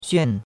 xuan4.mp3